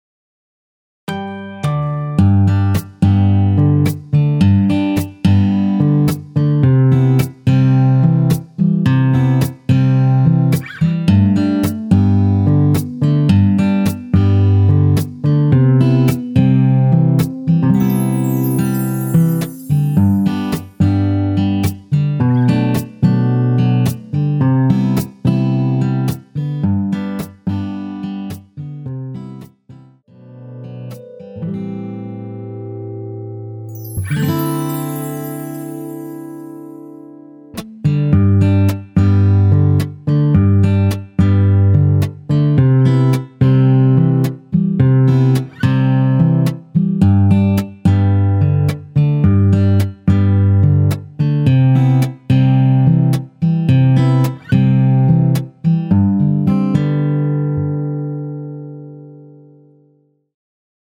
엔딩이 페이드 아웃이라 마지막 음~ 2번 하고 엔딩을 만들어 놓았습니다.(미리듣기 참조)
앞부분30초, 뒷부분30초씩 편집해서 올려 드리고 있습니다.